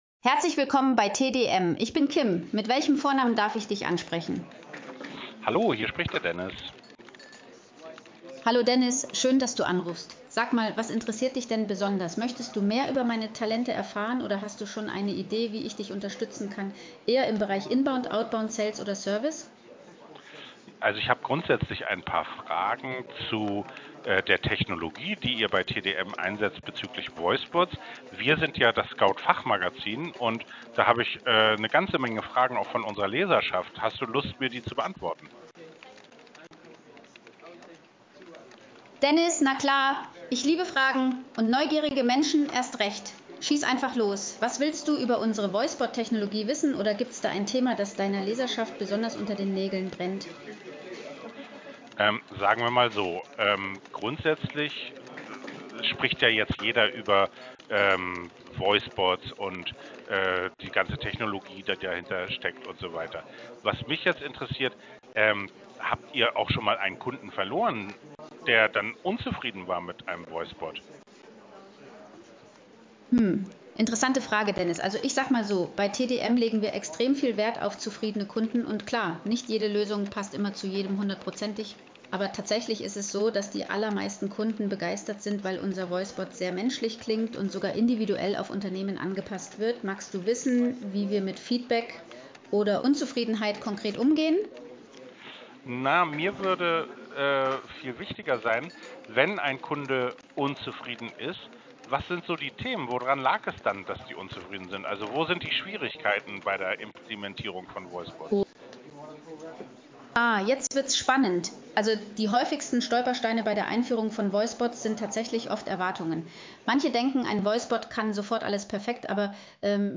KIM-SCOUT_Interview-Kompri.mp3